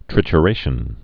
(trĭchə-rāshən)